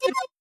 Alert4.wav